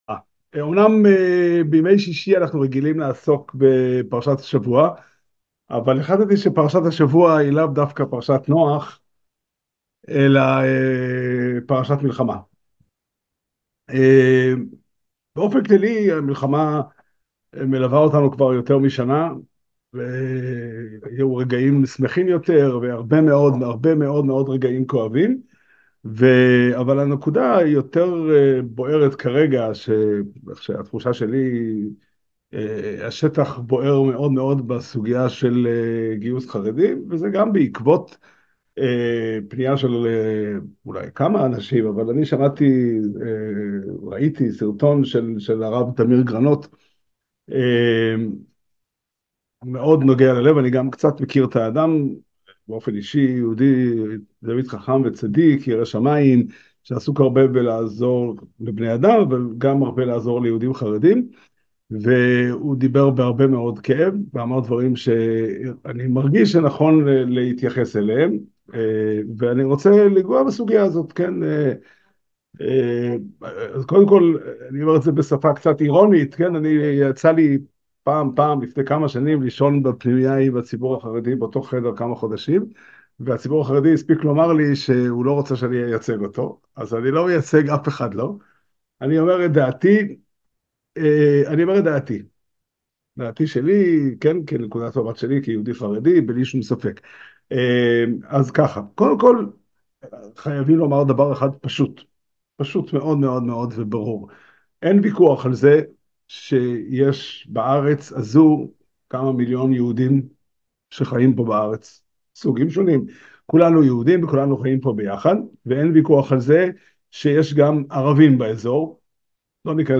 על המתח שבין שימור הזהות לבין ערבות ואחריות | שיעור מיוחד בענייני השעה